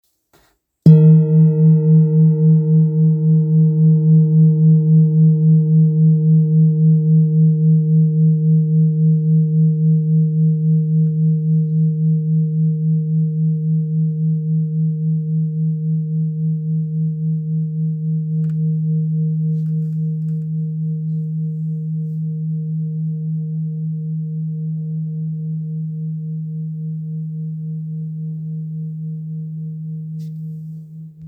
Kopre Singing Bowl, Buddhist Hand Beaten, Antique Finishing
Material Seven Bronze Metal
It is accessible both in high tone and low tone .